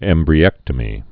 (ĕmbrē-ĕktə-mē)